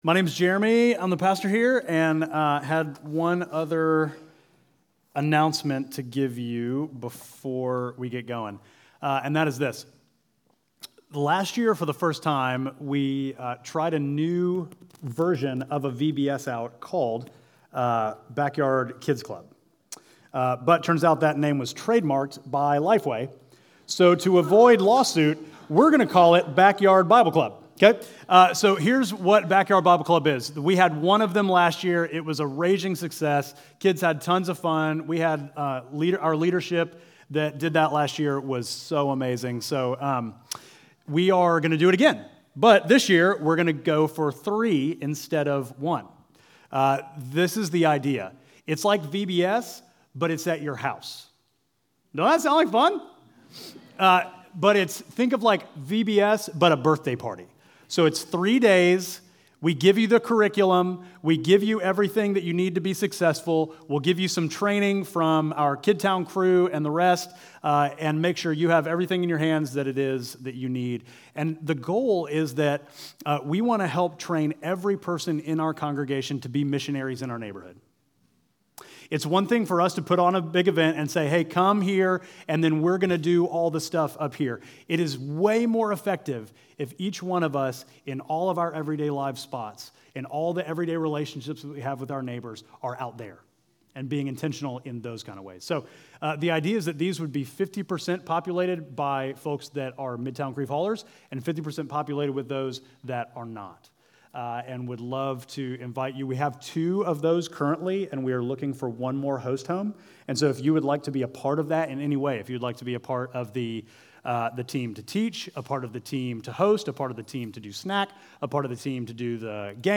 Midtown Fellowship Crieve Hall Sermons Peter’s Confusion May 05 2024 | 00:36:48 1x 00:00 / 00:36:48 Subscribe Share Apple Podcasts Spotify Overcast RSS Feed Share Link Embed